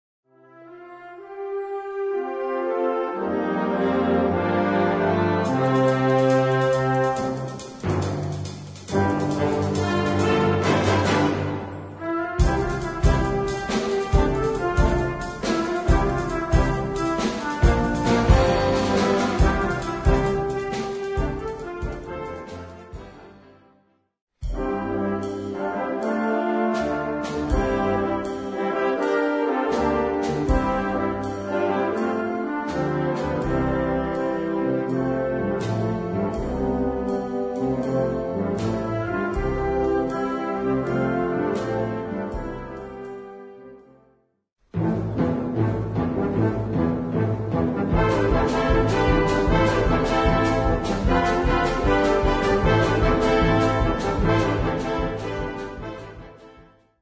Catégorie Harmonie/Fanfare/Brass-band
Sous-catégorie Musique de concert